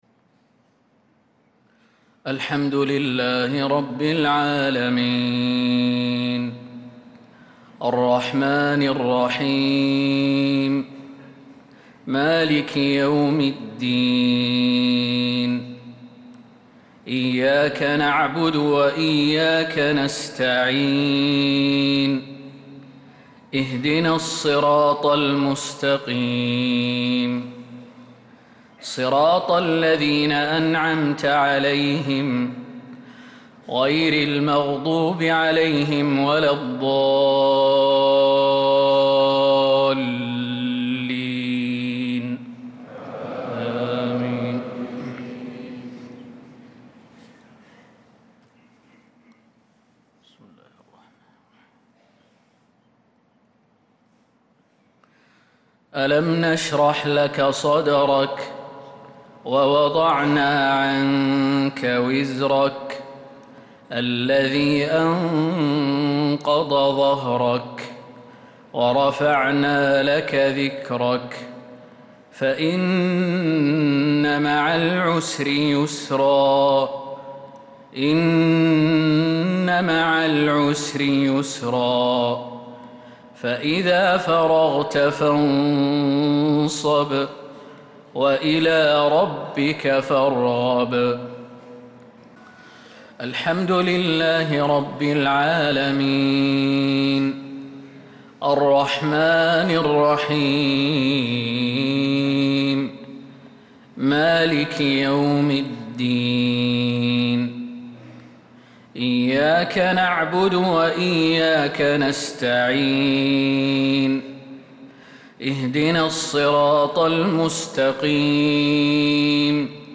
صلاة المغرب للقارئ خالد المهنا 12 ذو القعدة 1445 هـ
تِلَاوَات الْحَرَمَيْن .